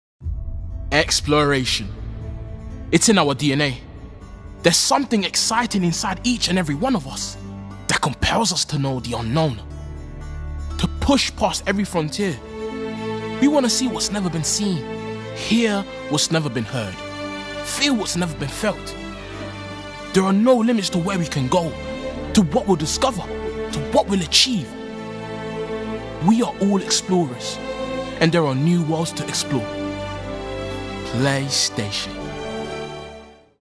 Voice Reel
Commercial - Playstation - Dynamic, Confident, Passionate